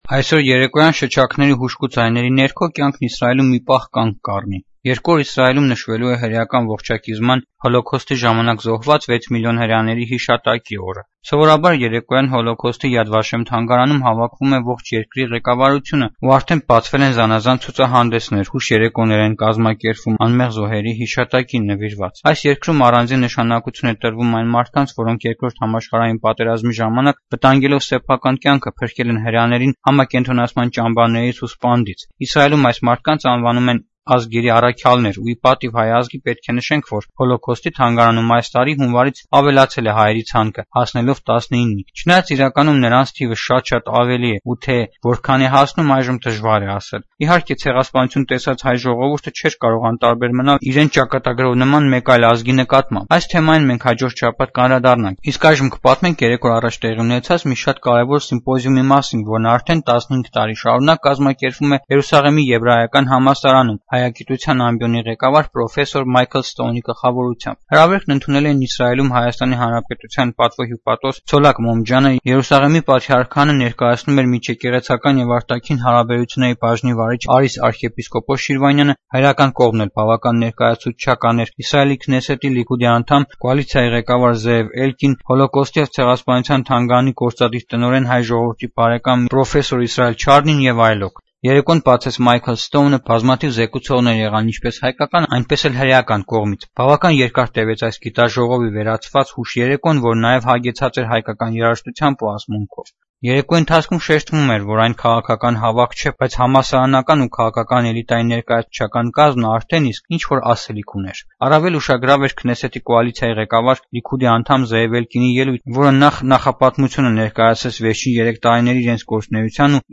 Ստորև առաջարկում ենք լսել «Ազատություն ռադիոկայանի» հայկական ծառայության հաղորդումը կապված այս երեկոյի հետ: